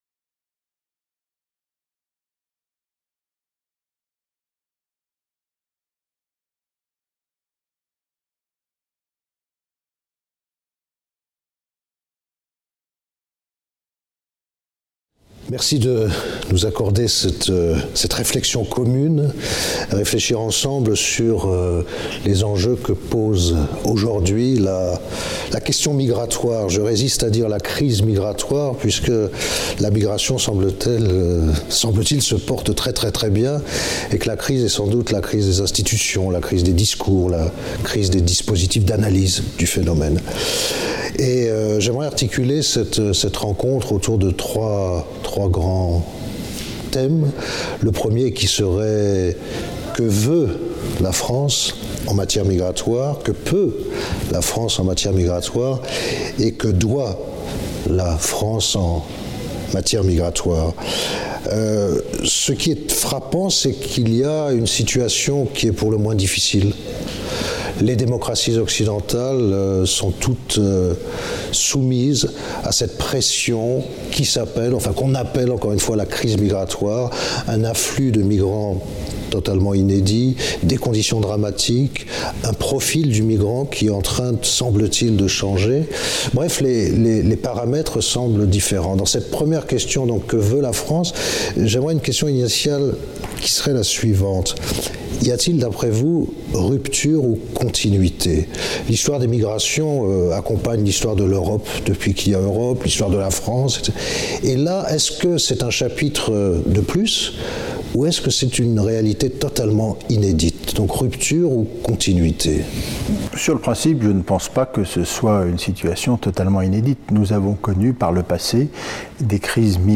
La France et sa politique migratoire. Entretien avec François-Noël Buffet, Les Républicains.